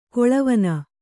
♪ koḷa vana